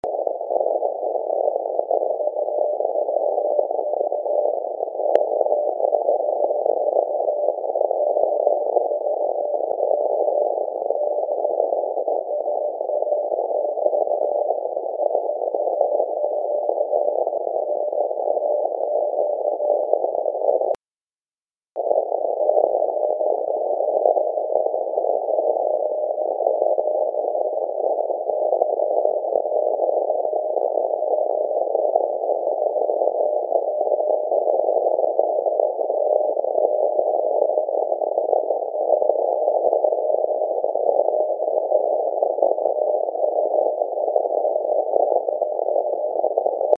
SAQ was received in CT USA. High static levels from thunderstorms over Gulf of Mexico, southern US and Carribean made for tougher than normal reception.